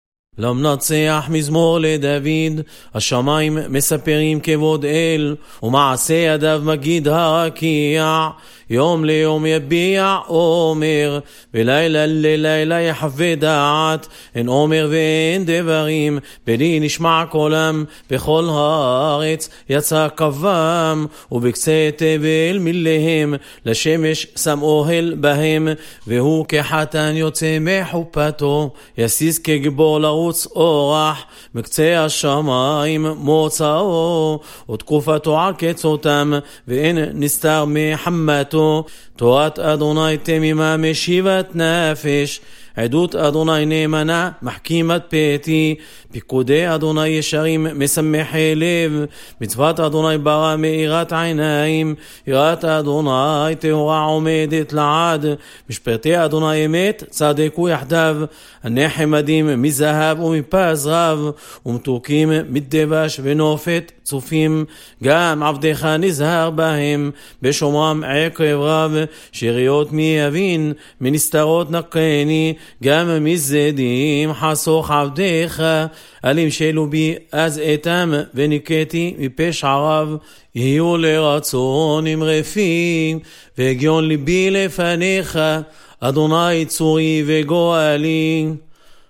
Sephardic minhag Aram Soba - Learn with proper taamim